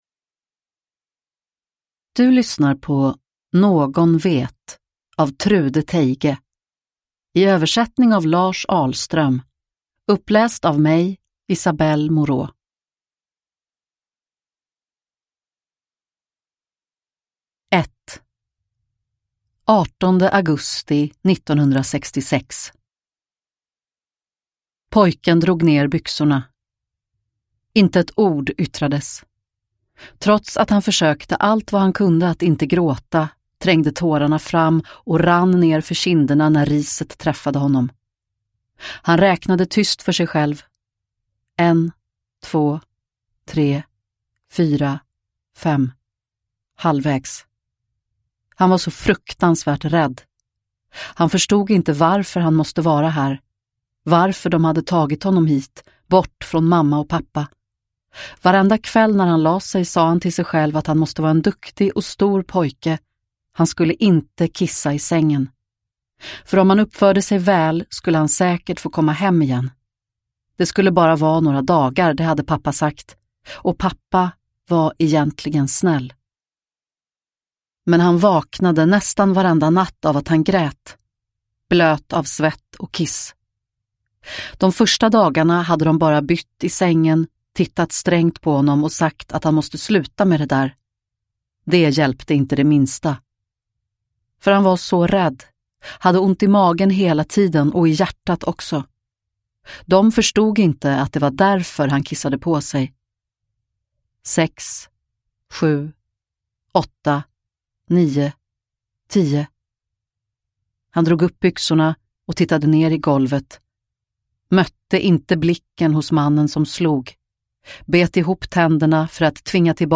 Någon vet – Ljudbok – Laddas ner